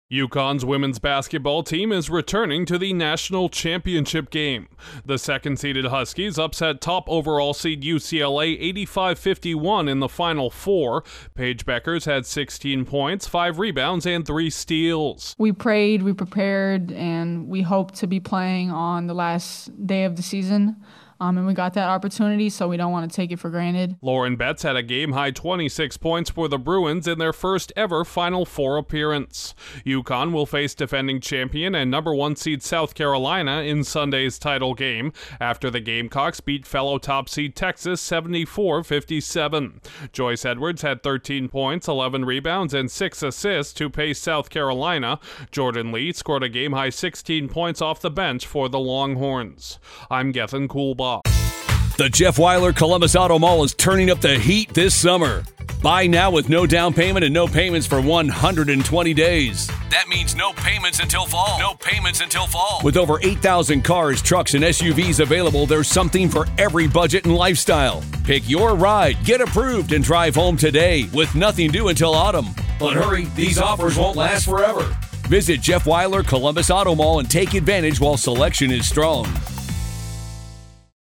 The women’s basketball national title game matchup has been set. Correspondent